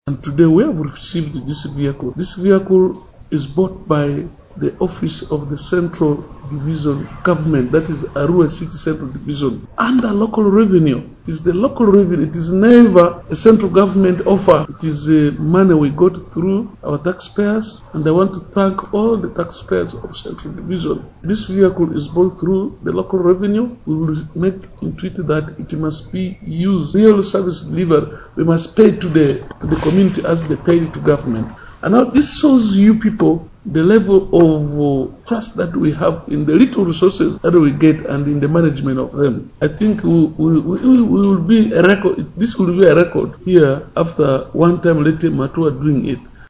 Mayor Khemis Muzaid took immense pride in unveiling the new acquisition during the commissioning event.
CUE;KHEMIS ON VEHICLE.mp3